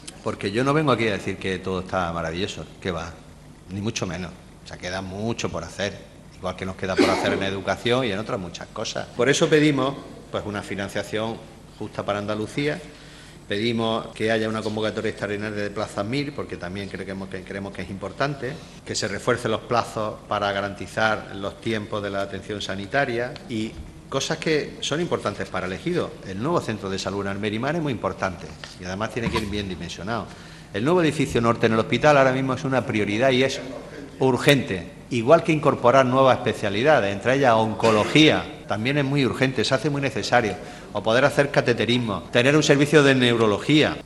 Durante el Pleno celebrado hoy, Góngora ha recordado al PSOE que “no se está desmantelando el Sistema Sanitario Público Andaluz.